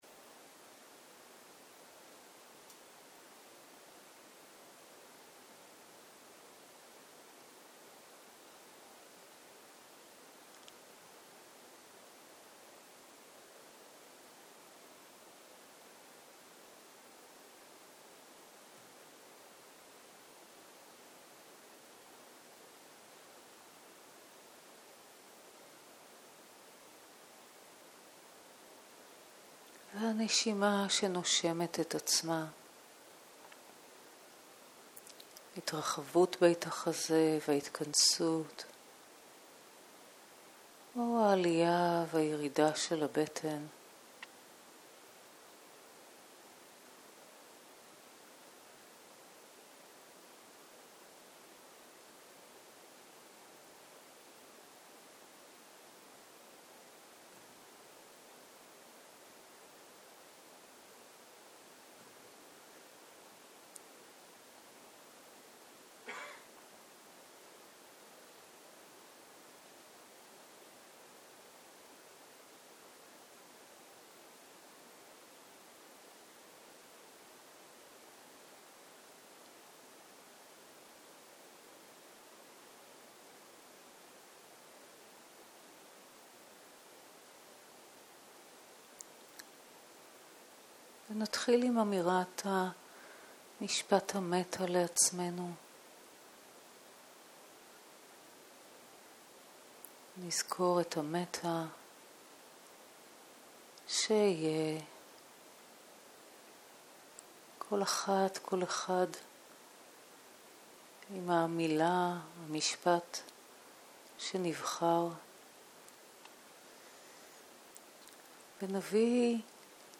צהרים - מדיטציה מונחית - משפטים לדמויות
סוג ההקלטה: מדיטציה מונחית